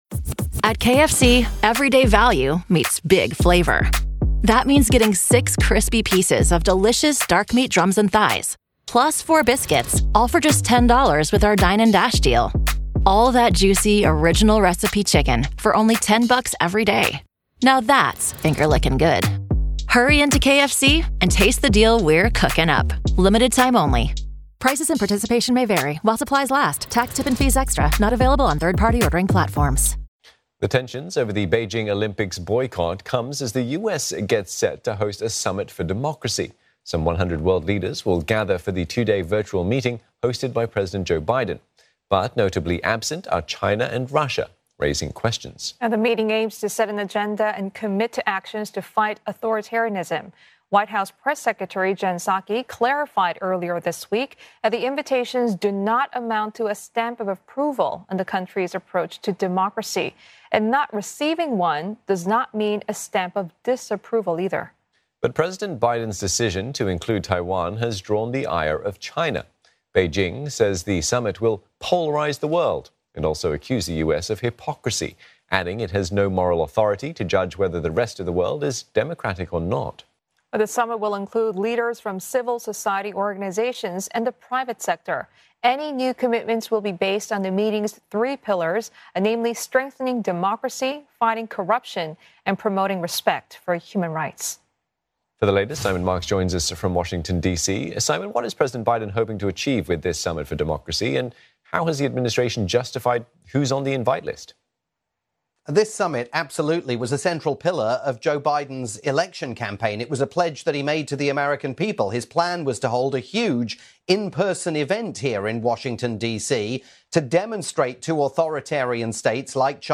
live report for CNA